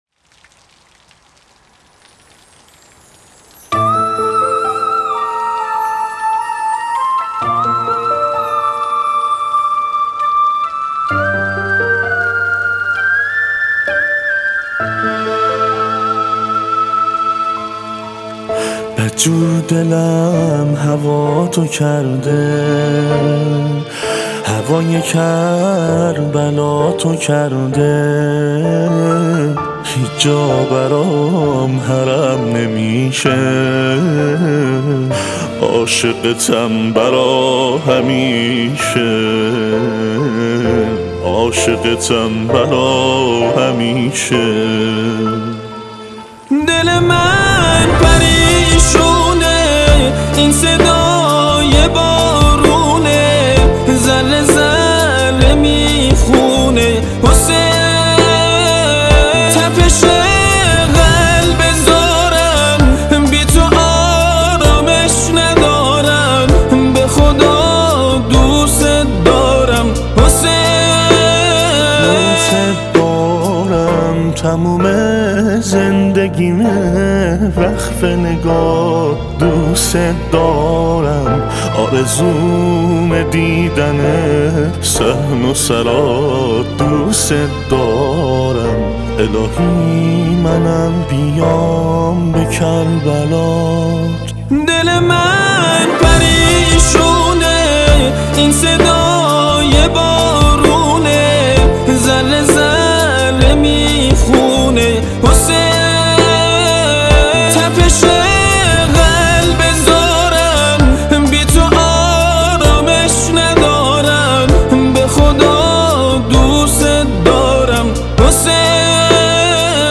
شاعر و خواننده مازندرانی
نواهنگ جدیدی را منتشر کرد.